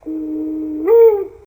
dove2.wav